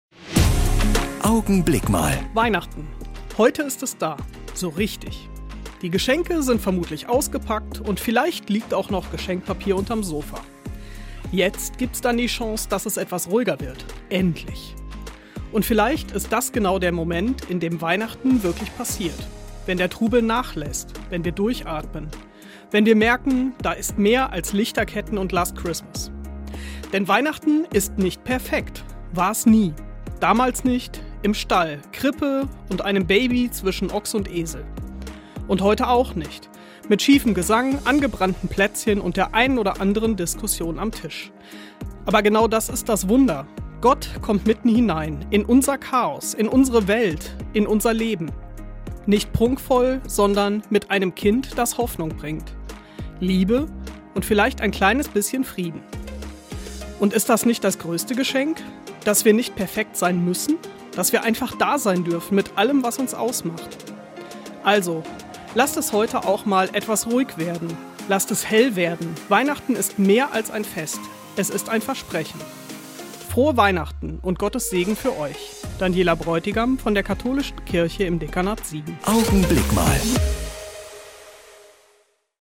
Jeden Sonntag und an Feiertagen gegen halb neun bei Radio Siegen zu hören: Die Kurzandacht der Kirchen (evangelisch und katholisch) - jetzt auch hier im